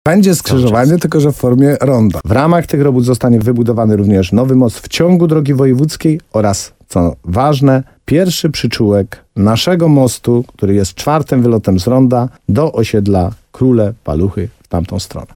O szczegółach tej inwestycji mówił w programie Słowo za Słowo w radiu RDN Nowy Sącz wójt Tadeusz Królczyk.